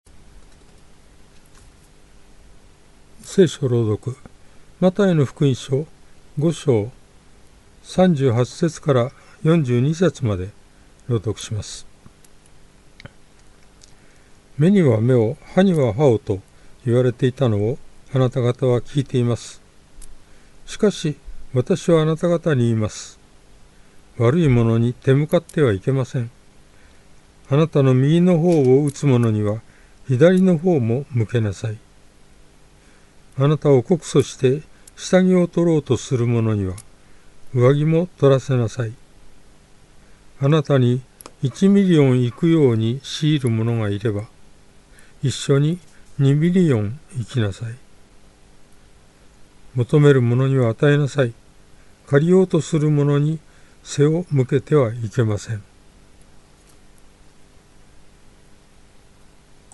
BibleReading_Math5.38-42.mp3